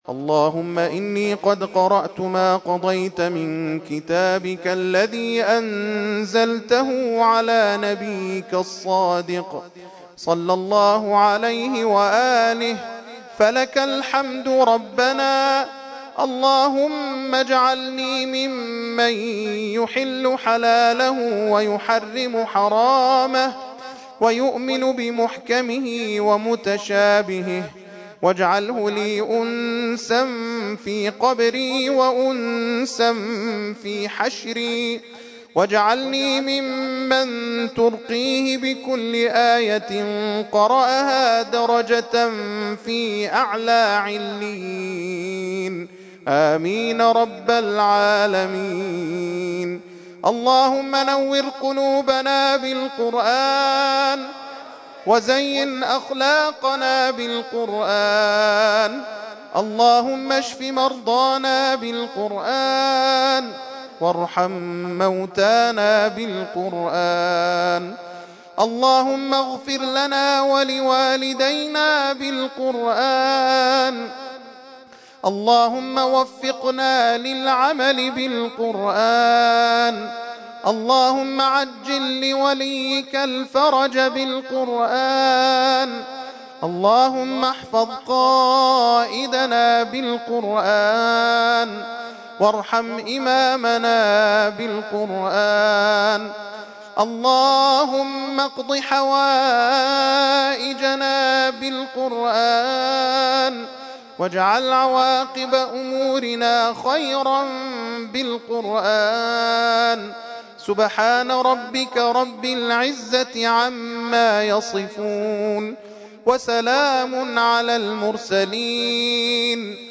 ترتیل خوانی جزء ۲۴ قرآن کریم در سال ۱۳۹۴